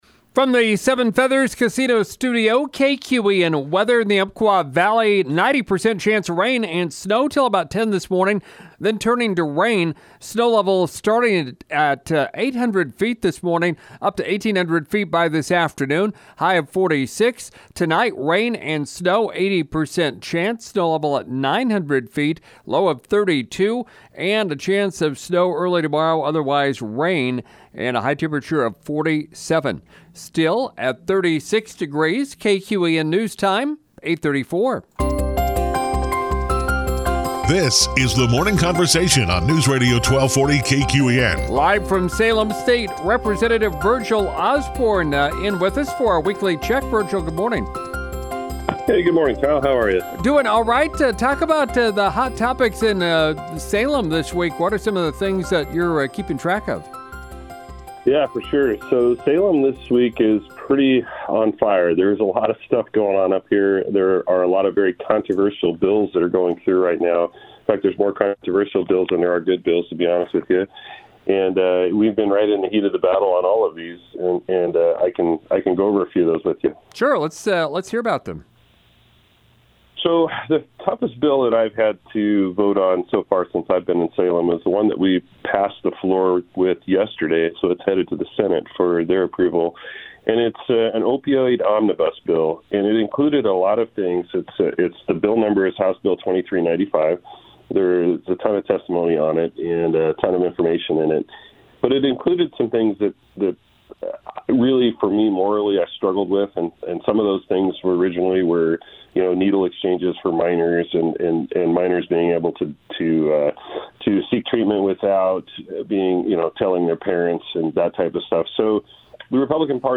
Live from Salem, State Representative Virgle Osborne talks about the Opioid Harm Reduction Package, a senate proposal that would ban feeding permits for confined animals and other topics.